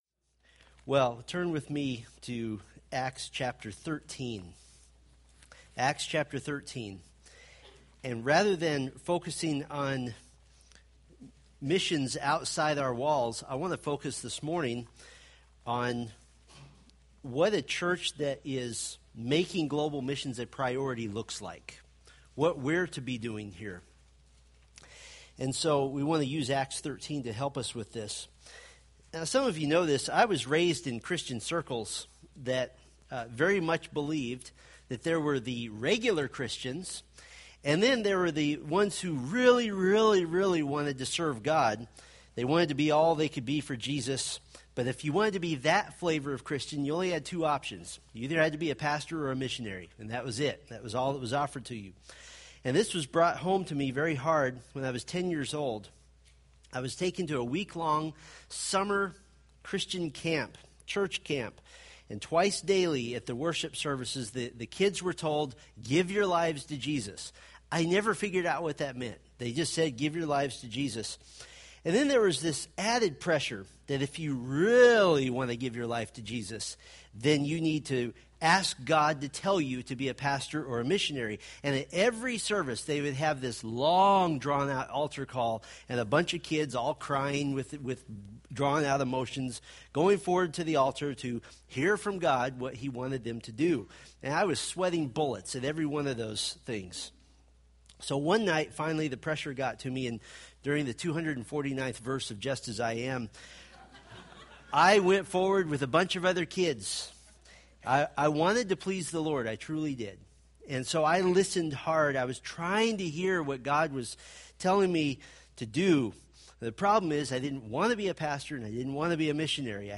Acts Sermon Series